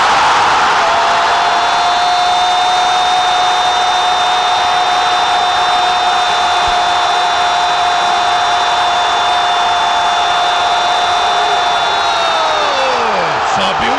radio-gol-palmeiras-1-x-1-gremio-radio-estadao-espn-narracao-de-gustavo-villani.mp3